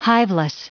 Prononciation du mot hiveless en anglais (fichier audio)
Prononciation du mot : hiveless